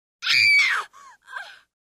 Звуки людей
Девушка заметила мышь